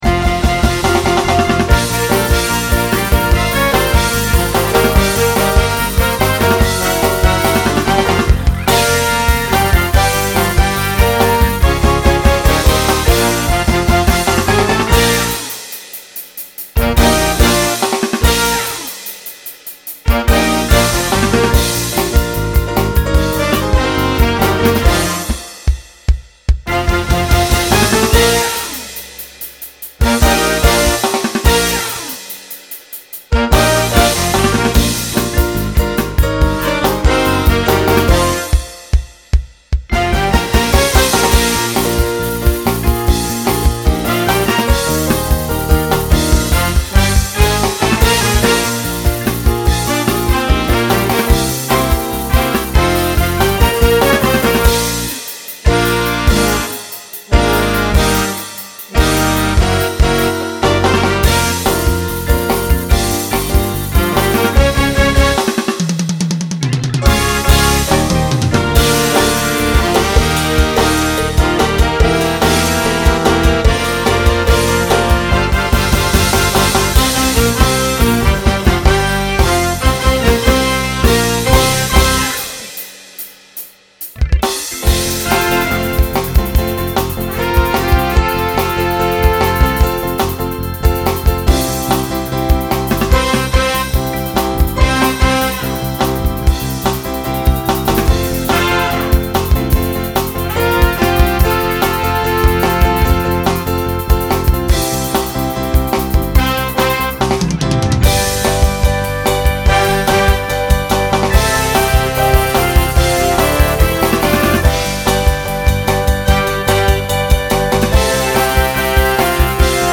Mixed SATB
SATB Instrumental combo Genre Rock Decade 1960s